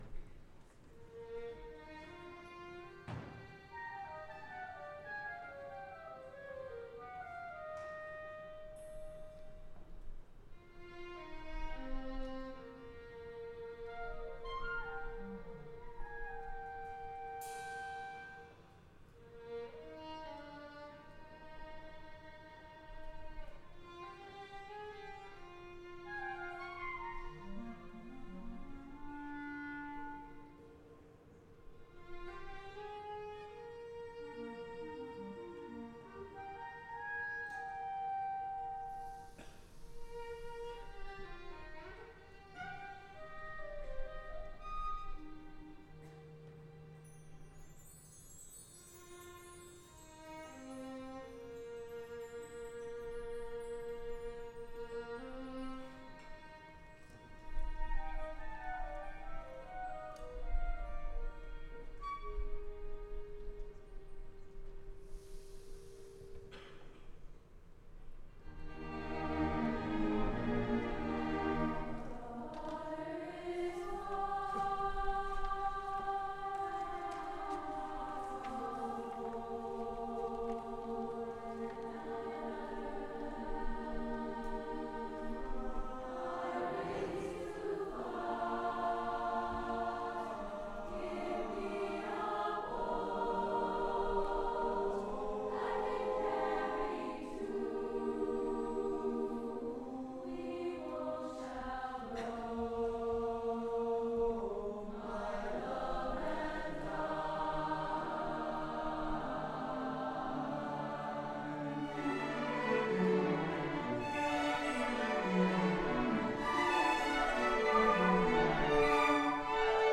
Brookline High School Spring Music Festival
Combined Ensembles